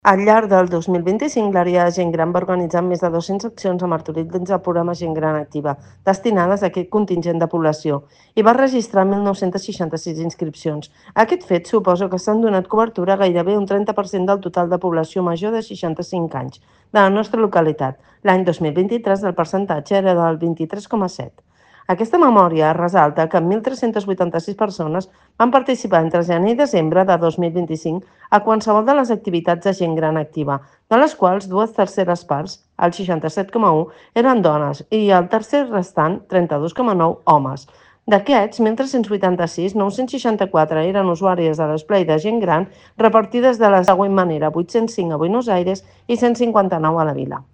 Montserrat Salas, regidora de Gent Gran de l'Ajuntament de Martorell